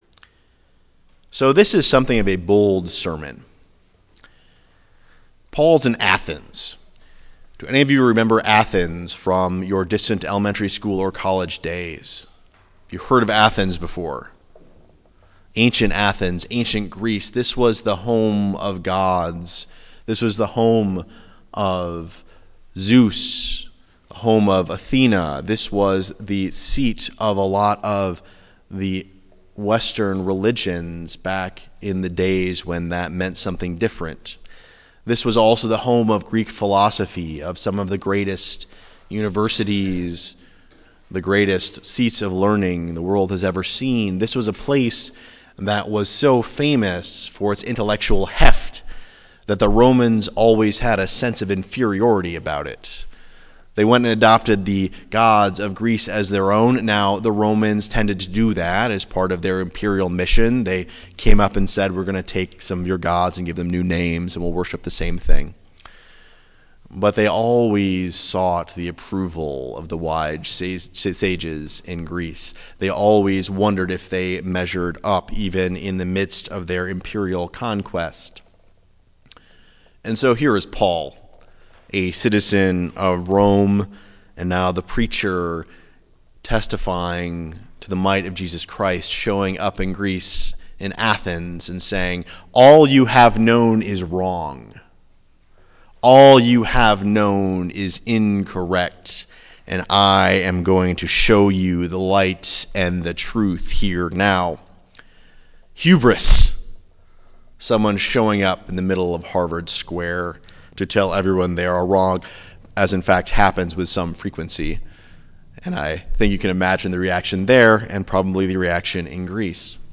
Preached May 25, 2014 in Ankeny UCC Acts 17:22-31